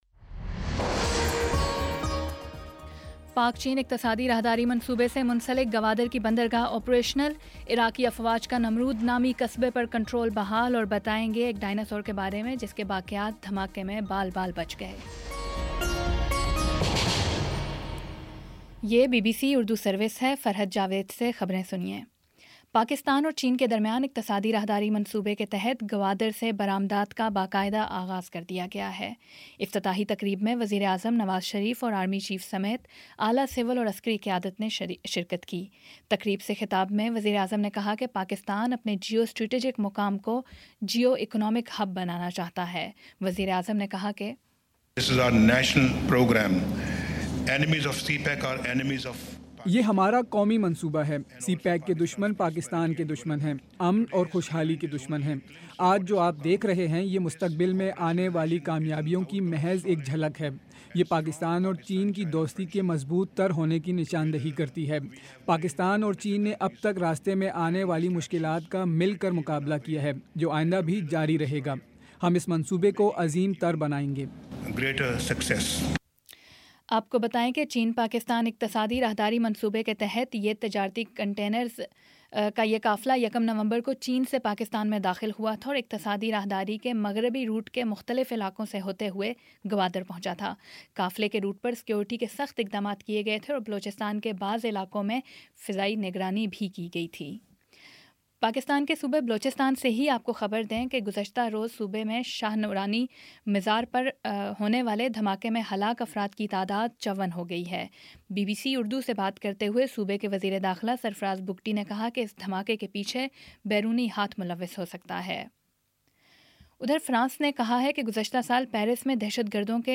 نومبر 13 : شام سات بجے کا نیوز بُلیٹن